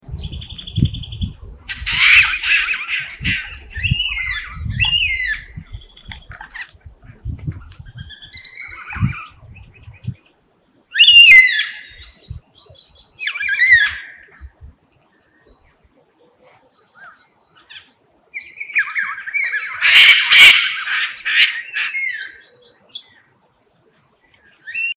Listen to the sound of a Carnabys Black Cockatoo (courtesy of Kaarakin Black Cockatoo Conservation).
Carnabys2.mp3